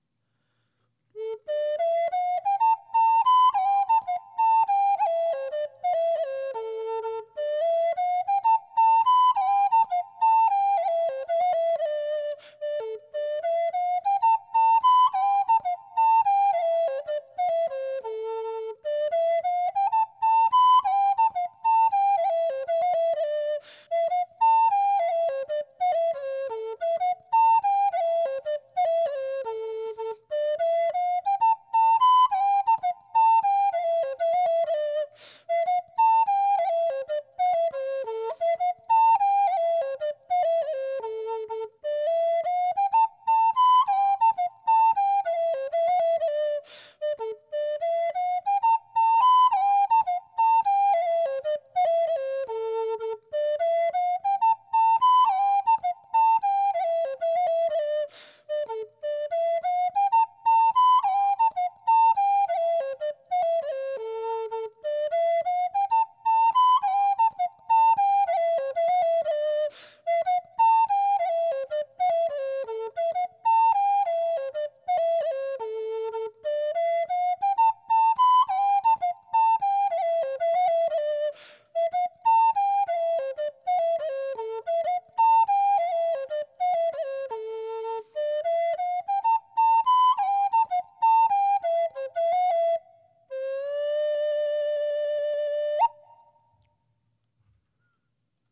Jouer de la flûte Amérindienne, c'est facile!